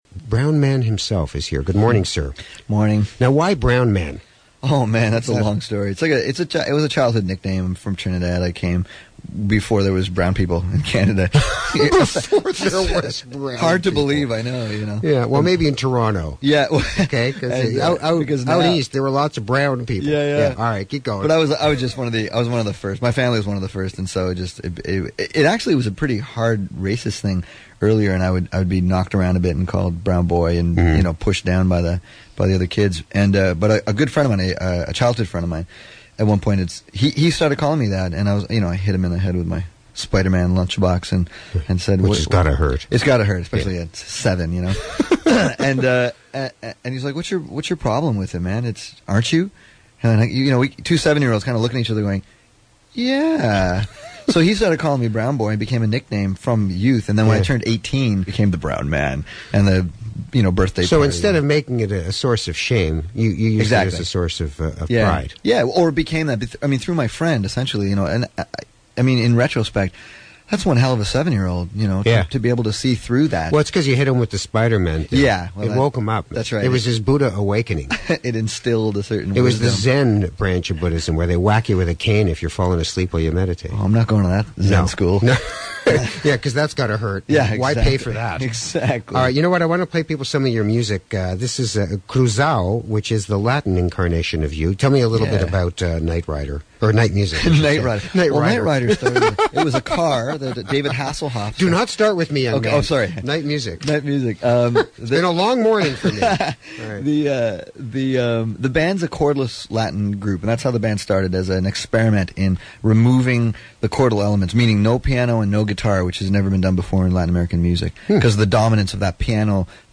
radio (streaming MP3) Audio Interview